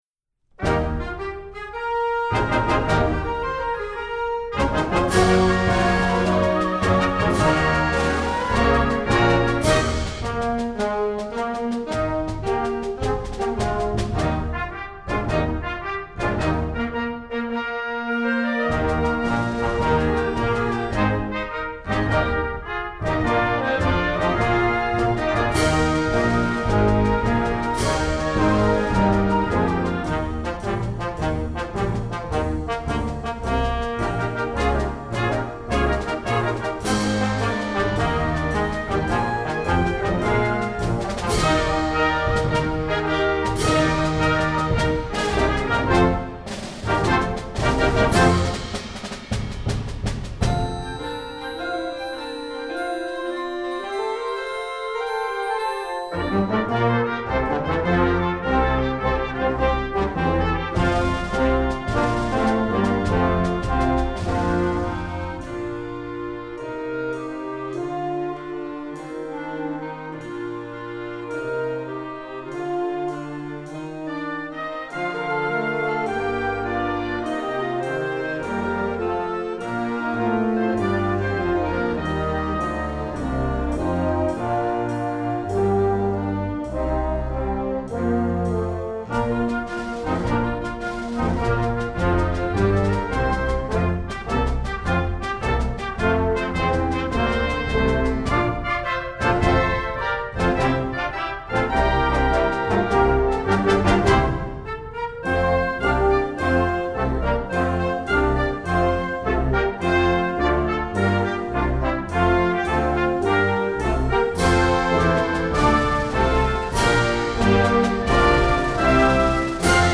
novelty